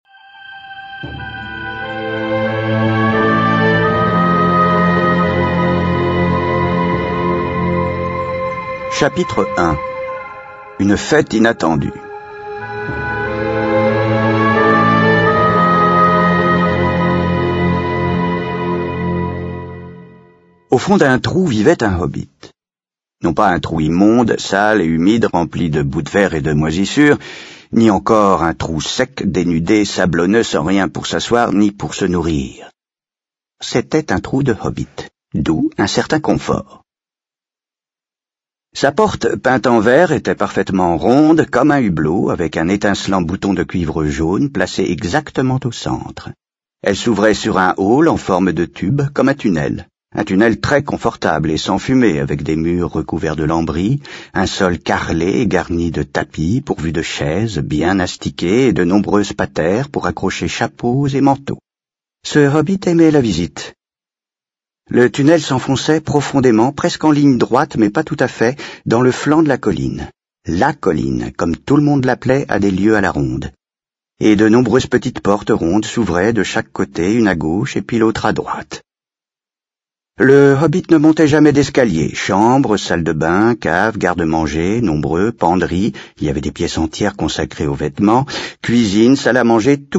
Le Hobbit - Livre audio
Lecture d'ailleurs de la nouvelle traduction si je ne m'abuse.